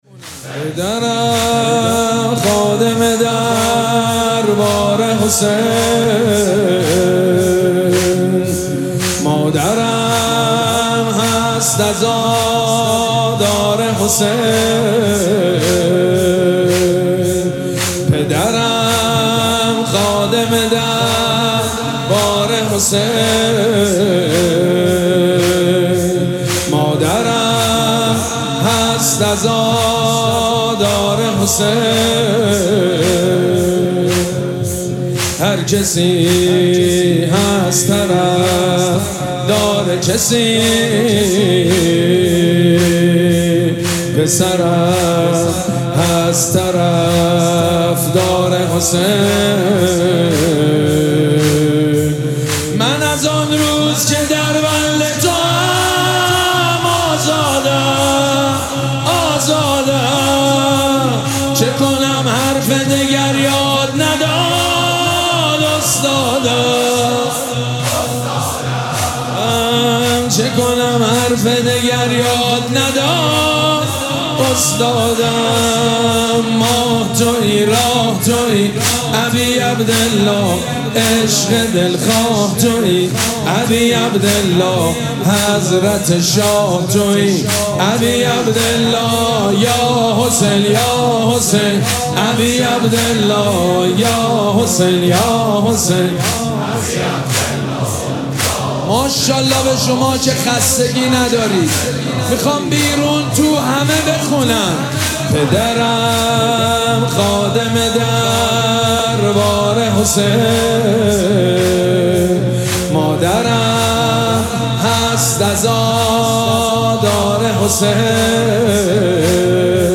مراسم عزاداری شام غریبان محرم الحرام ۱۴۴۷
مداح
حاج سید مجید بنی فاطمه